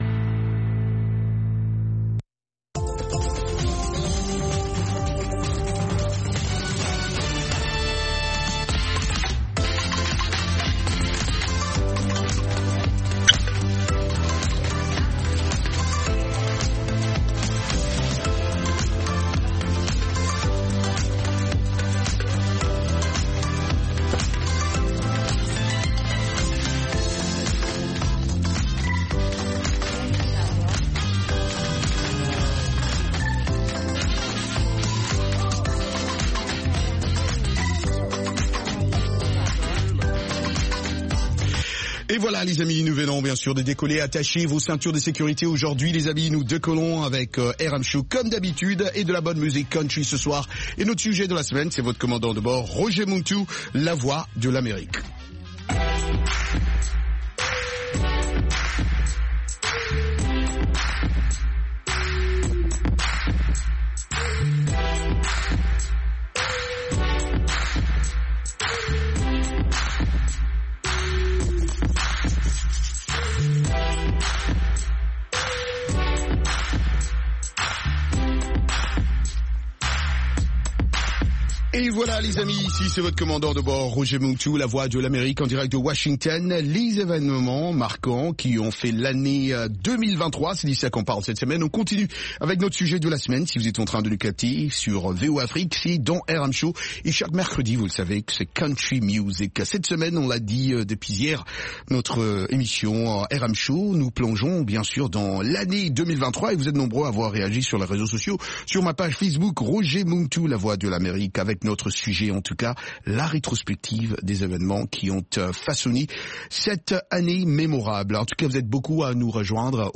RM Show -Musique internationale & comedie